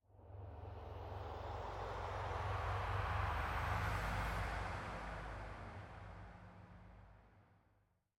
Minecraft Version Minecraft Version latest Latest Release | Latest Snapshot latest / assets / minecraft / sounds / ambient / nether / nether_wastes / addition3.ogg Compare With Compare With Latest Release | Latest Snapshot